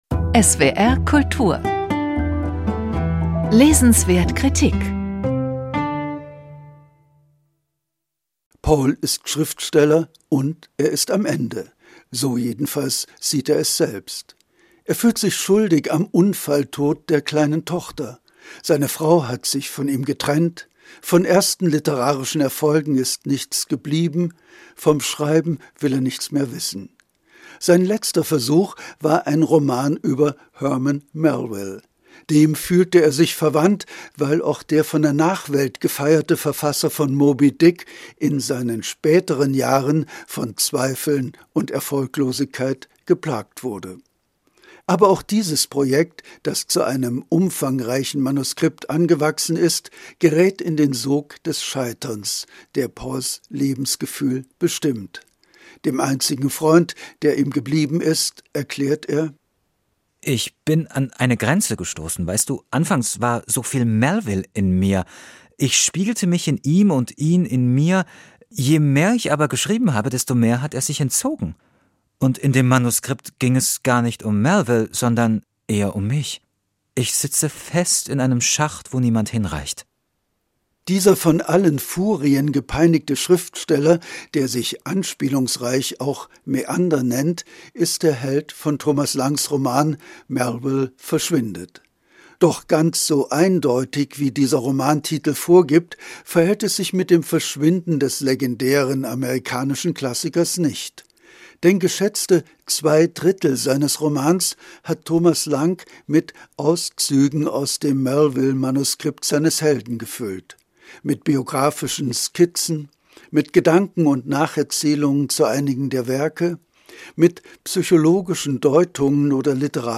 Rezension